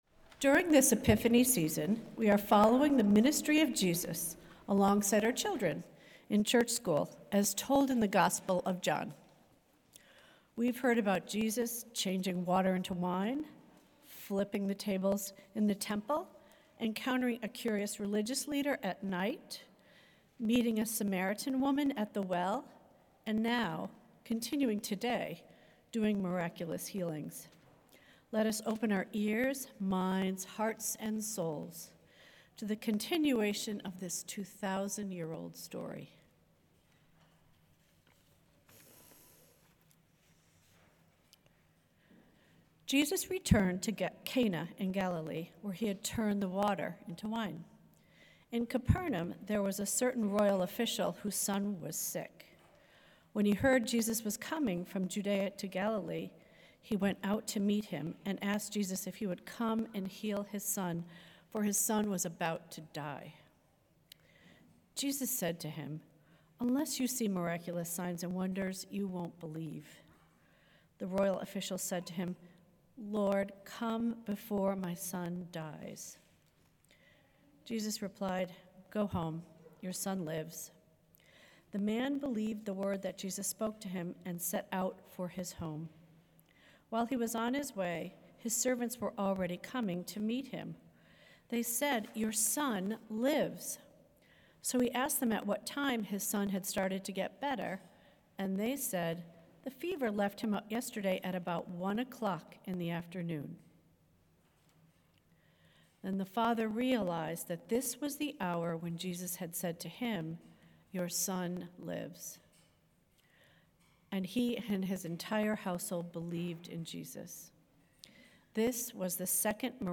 Sunday-Sermon-February-8-2026.mp3